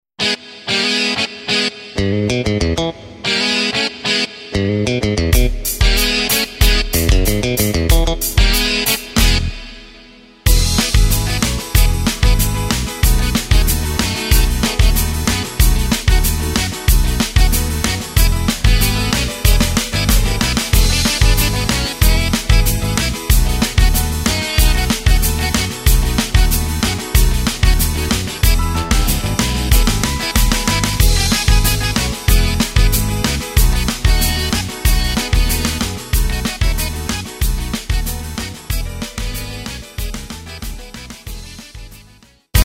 Takt:          4/4
Tempo:         187.00
Tonart:            E
Country Klassiker aus dem Jahr 1999!
Playback mp3 Demo